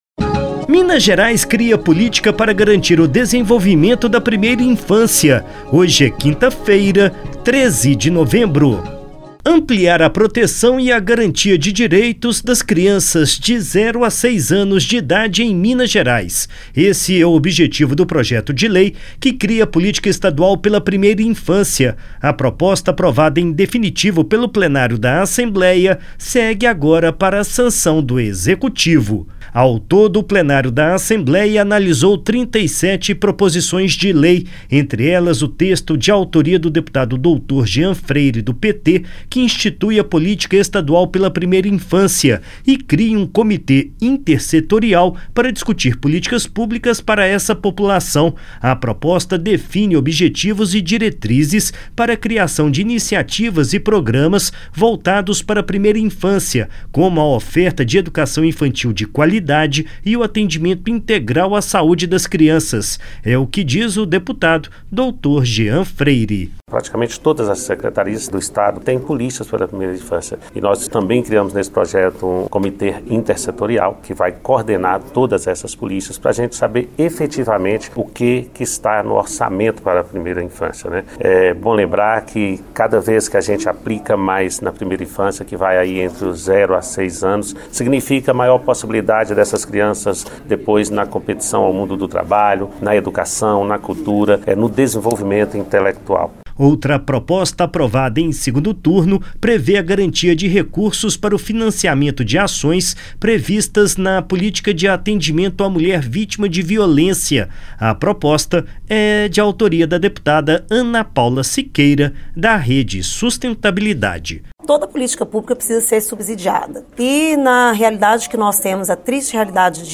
Boletim da ALMG - Edição n.º 6295